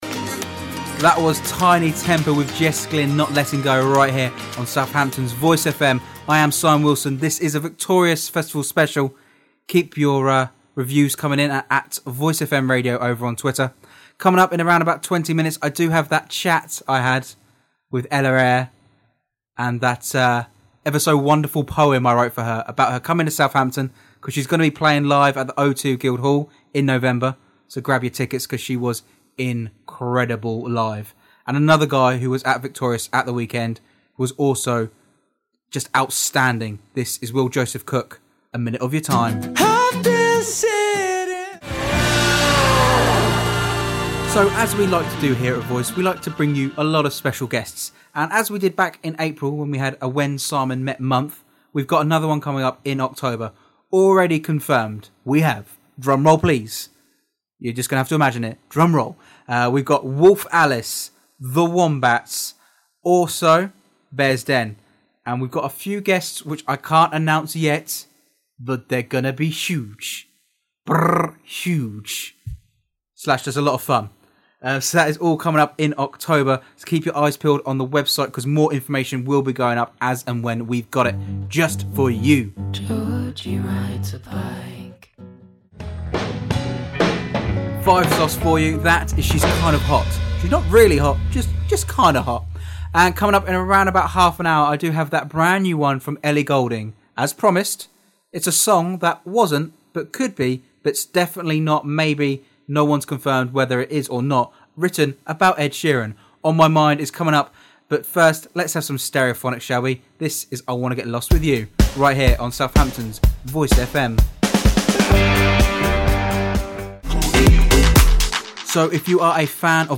My radio presenting demo for August/September